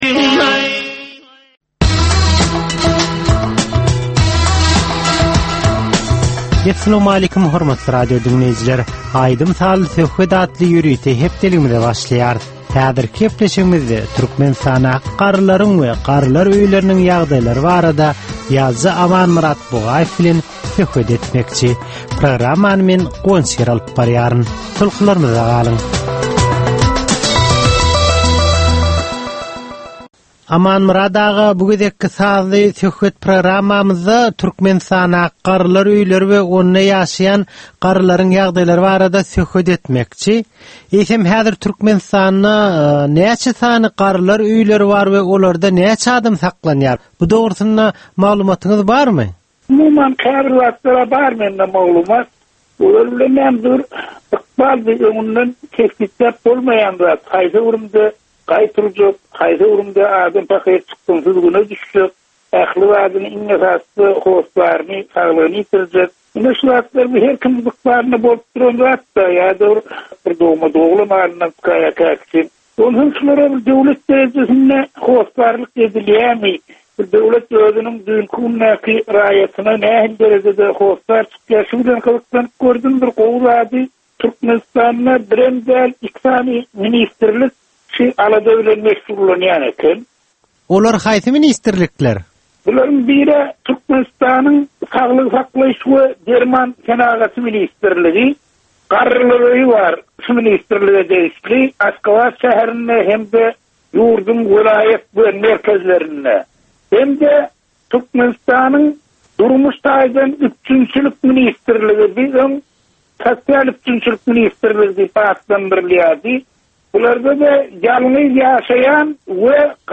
Türkmenin käbir aktual meseleleri barada 30 minutlyk sazly-informasion programma